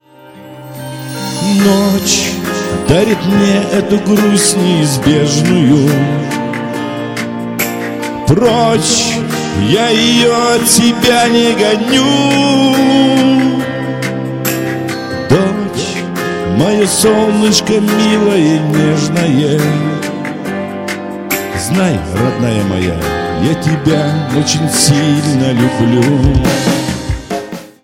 душевные
спокойные